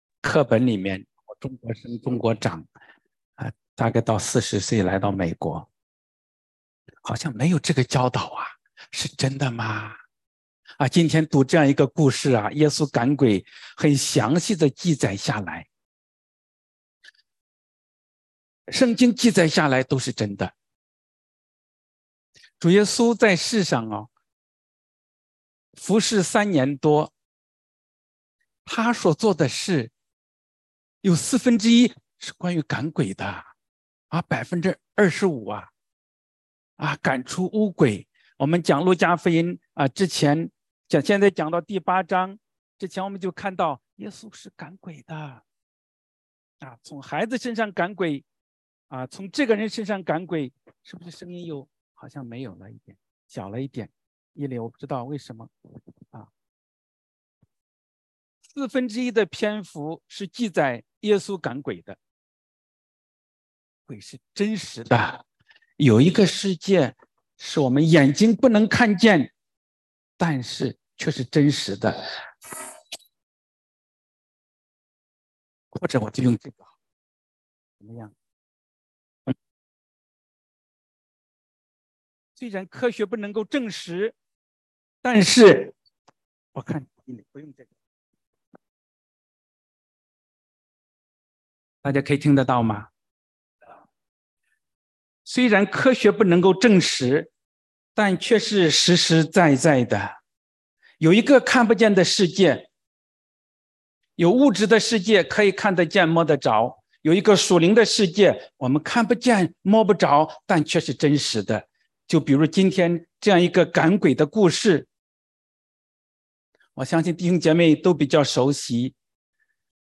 Posted in 福音 ← Newer Sermon Older Sermon →